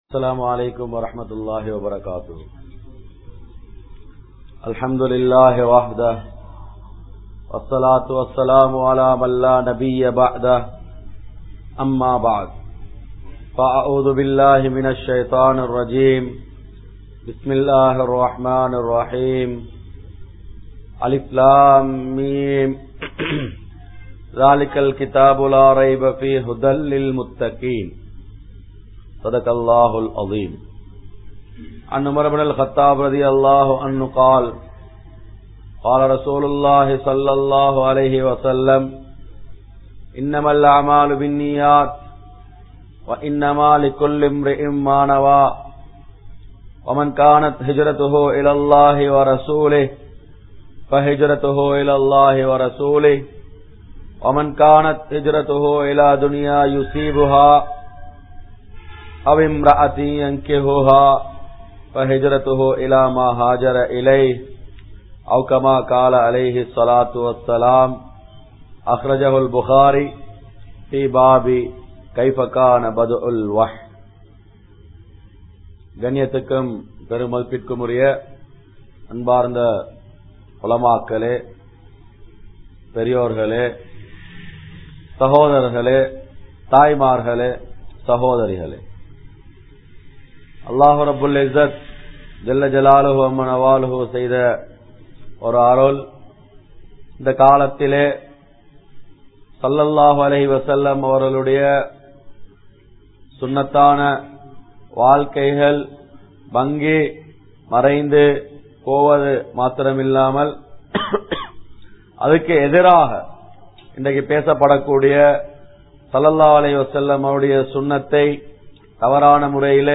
Kuli Thoandi Puthaikkapatta Islamiya Maruththuvam (குழி தோண்டி புதைக்கபட்ட இஸ்லாமிய மருத்துவம்) | Audio Bayans | All Ceylon Muslim Youth Community | Addalaichenai
Muhiyaddeen Grand Jumua Masjith